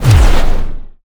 sfx_skill 17_1.wav